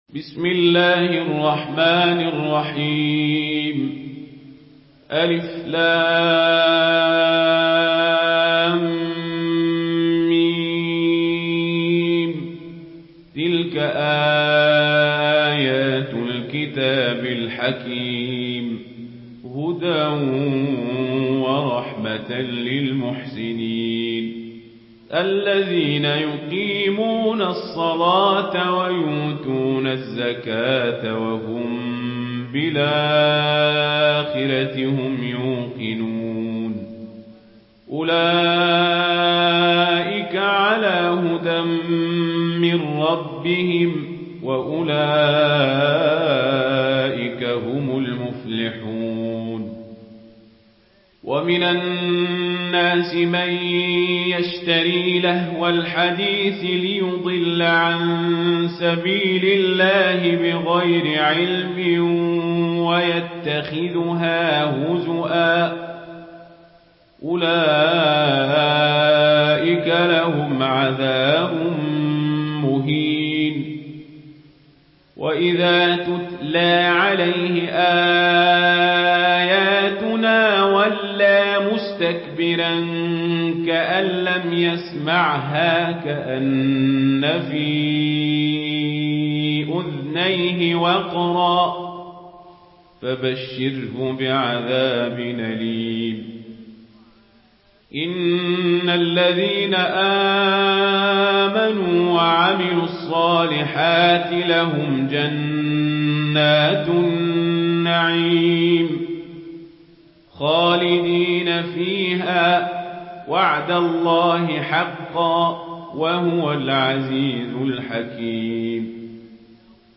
Surah لقمان MP3 by عمر القزابري in ورش عن نافع narration.
مرتل ورش عن نافع